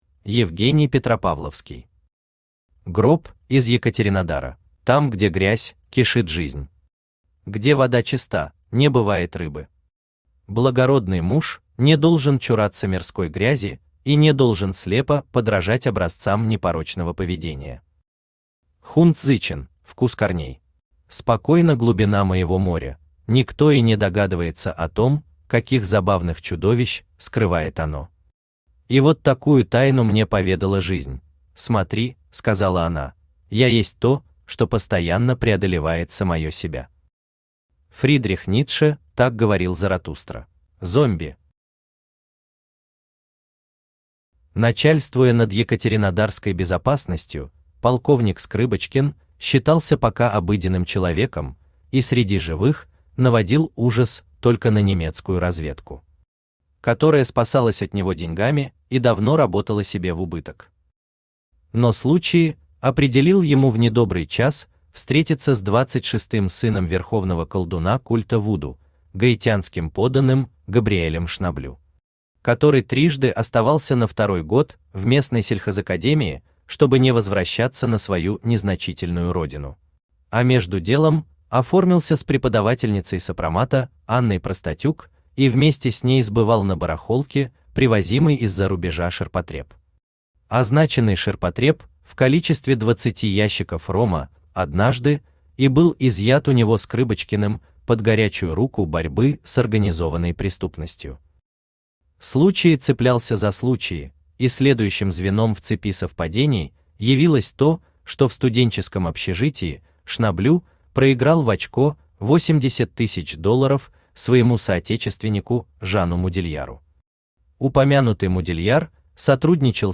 Аудиокнига Гроб из Екатеринодара | Библиотека аудиокниг
Aудиокнига Гроб из Екатеринодара Автор Евгений Петропавловский Читает аудиокнигу Авточтец ЛитРес.